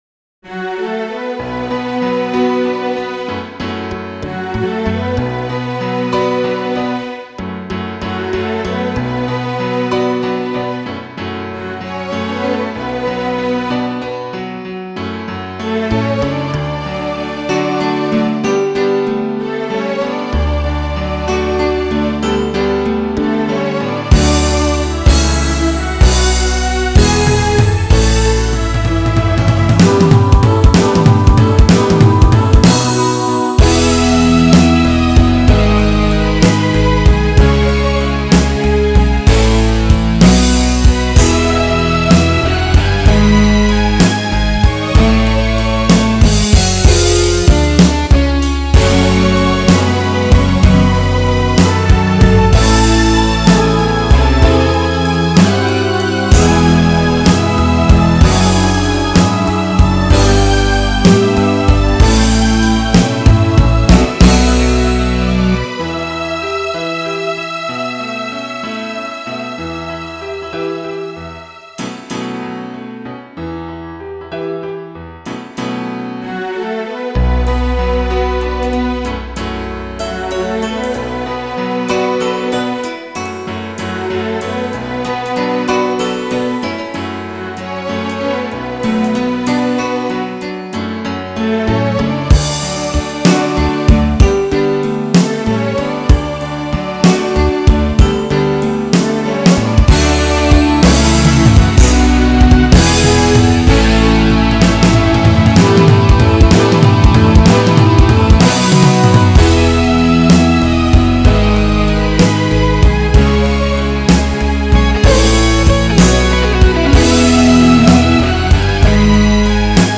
The song builds and builds, then drops to a whisper.